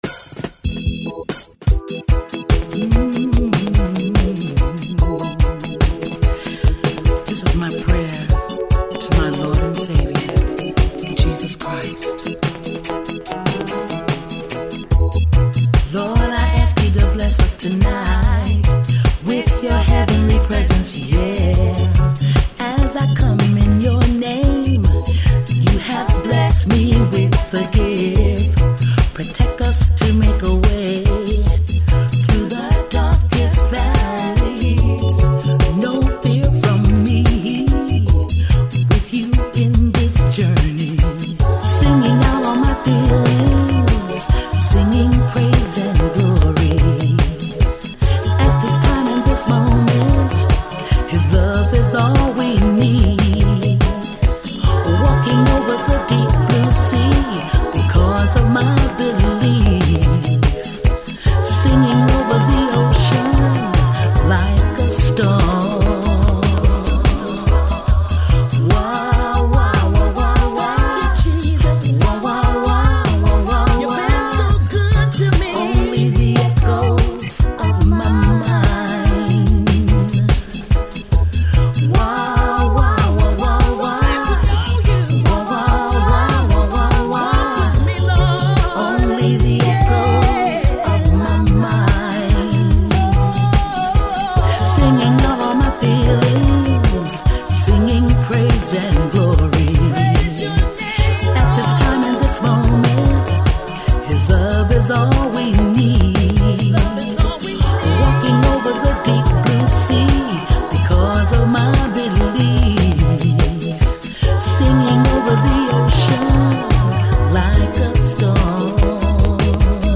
is an American R&B and dance music singer.